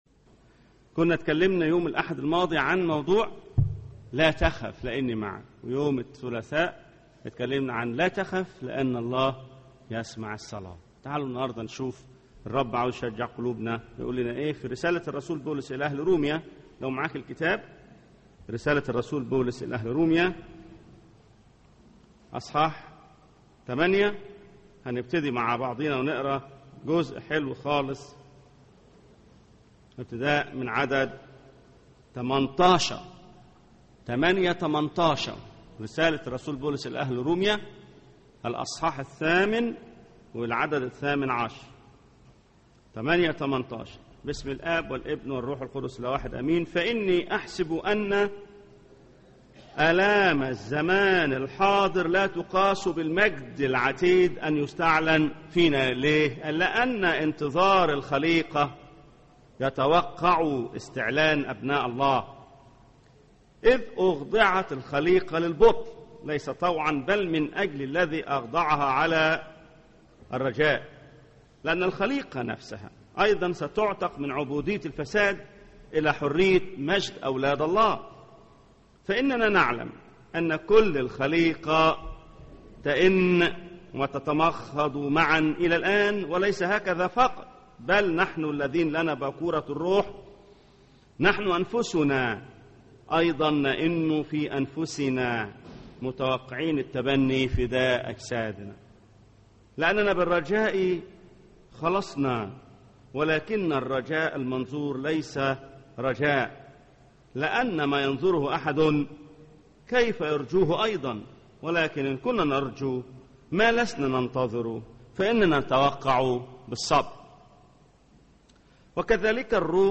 سلسلة عظات بعنوان “لا تخف”سبع عظات يواجه فيها الله خوفنا من كلمته..